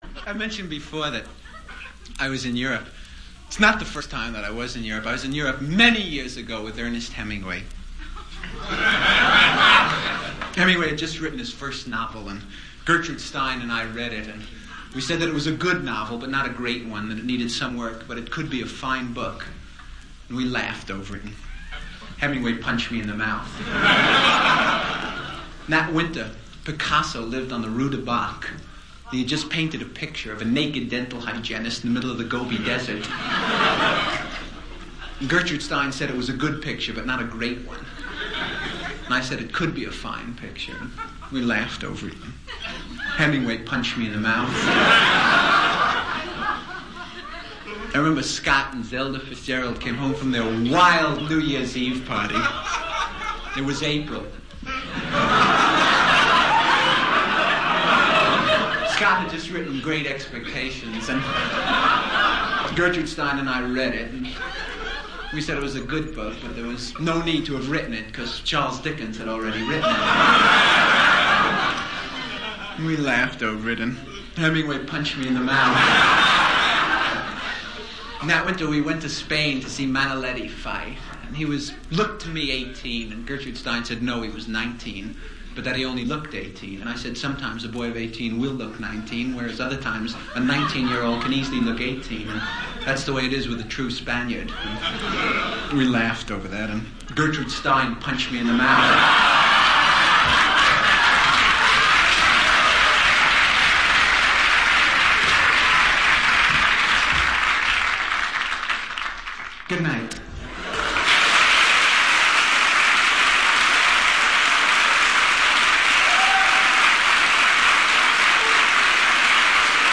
伍迪单口相声精选 第13期:迷惘的一代The Lost Generation 听力文件下载—在线英语听力室